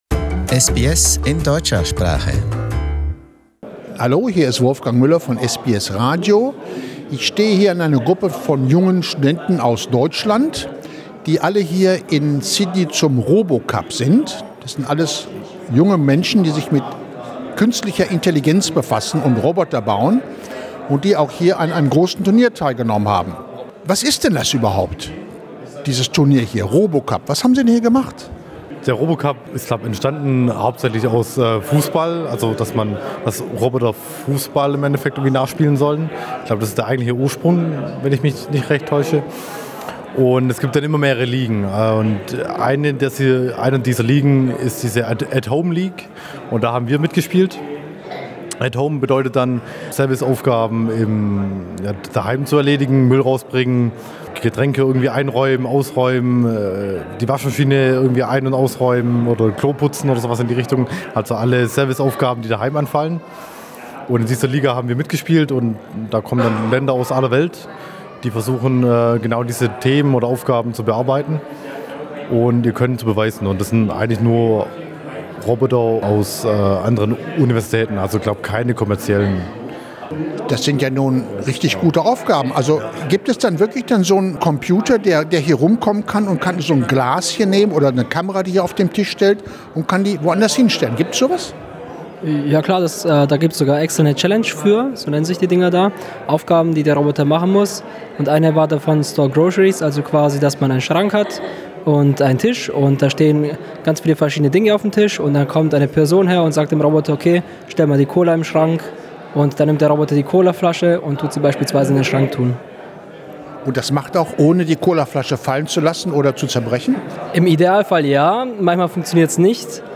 RT Lions im Interview Source